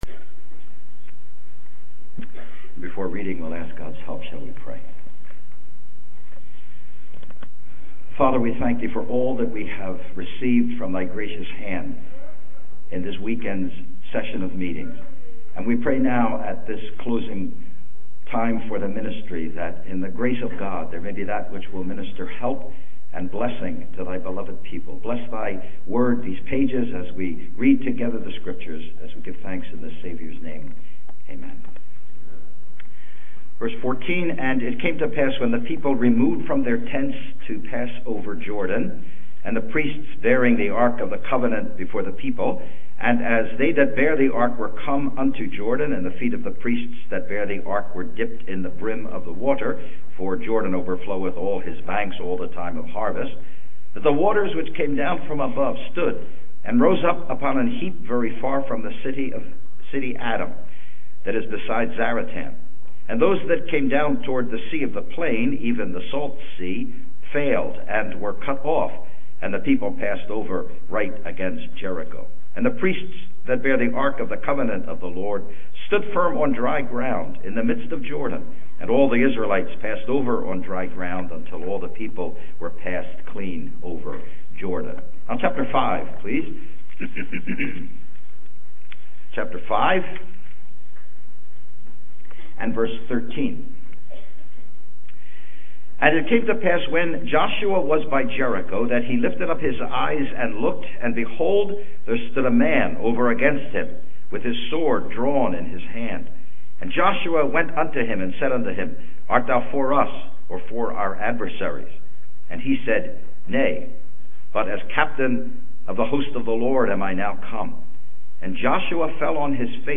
2019 PGH Annual Conference